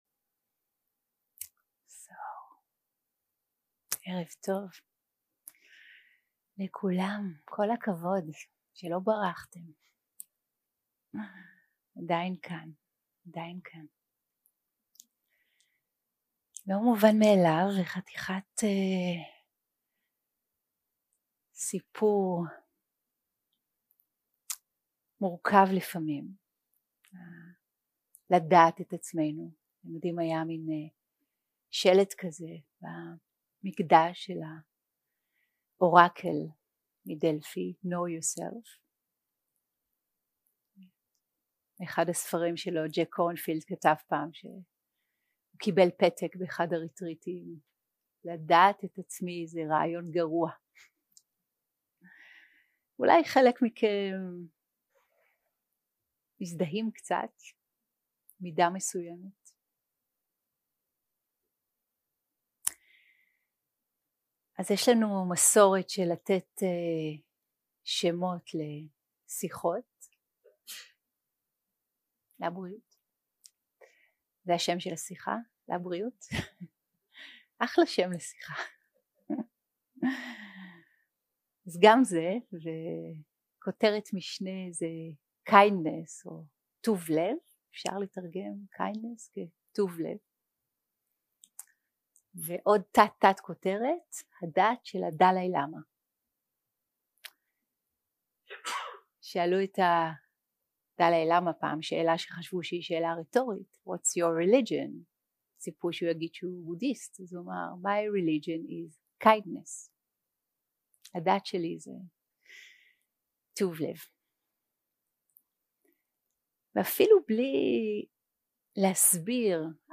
יום 3 – הקלטה 7 – ערב – שיחת דהארמה – טוב לב
Dharma type: Dharma Talks